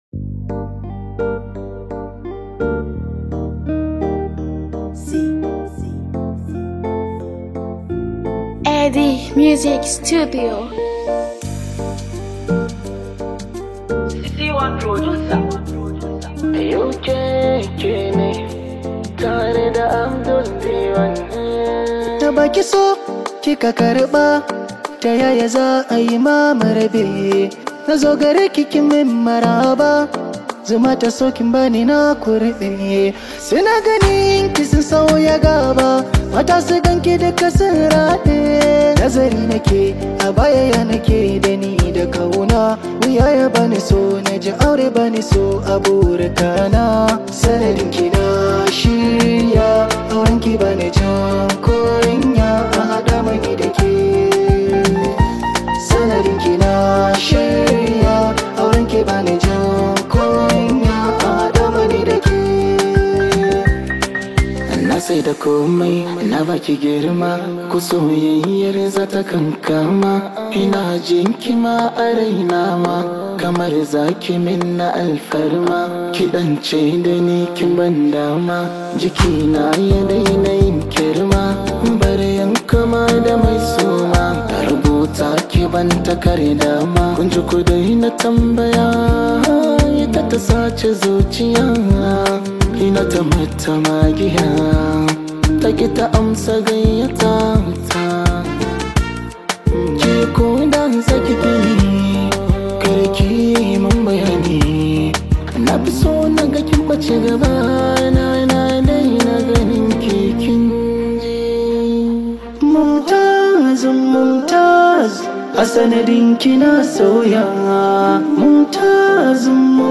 Nigerian singer-songsmith